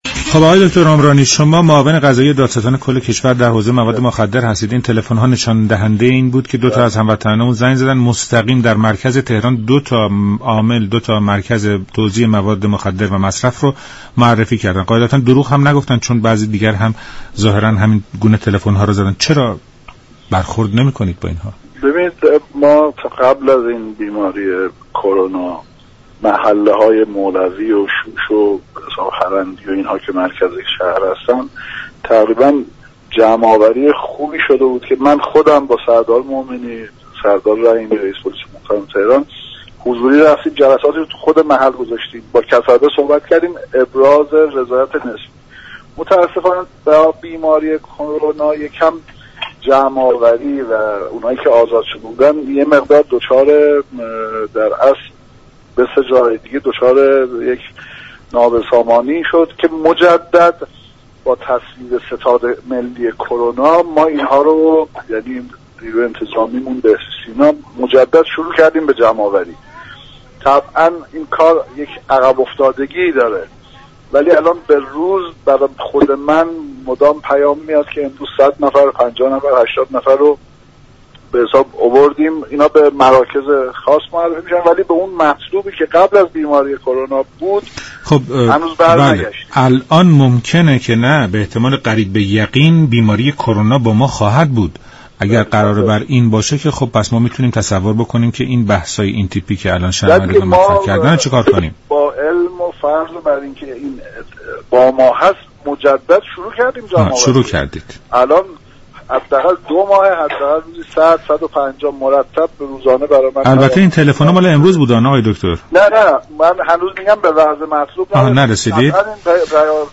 معاون قضایی دادستان كل كشور در رادیو ایران: كرونا پاكسازی مراكز توزیع مواد مخدر را با مشكل روبرو كرد